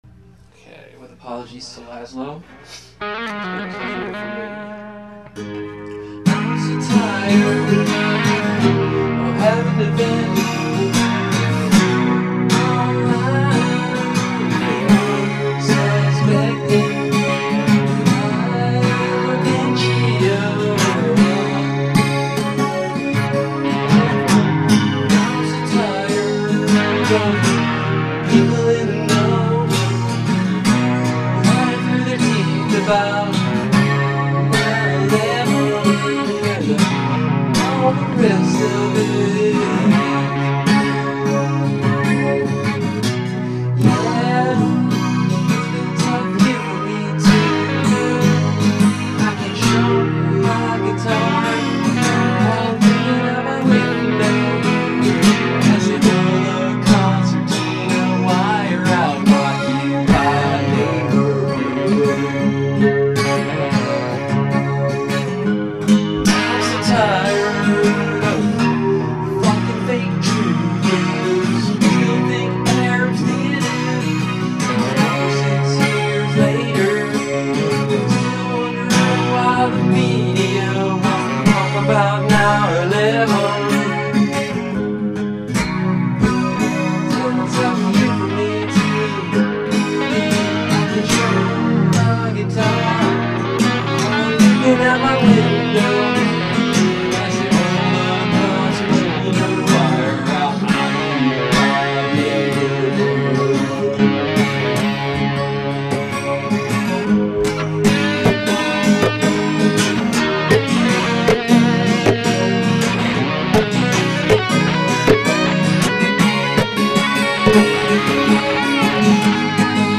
Existential pathos in the voice.
thanks man! i know i can do better when i have more time, especially the vocals, probably should do them as a separate track from the acoustic next time. anyway if you want to round out the words i can try to tighten it up for next time i have some peace and quiet in a week or 2
Very Neil Young, and impressive!